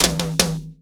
TOM     1C.wav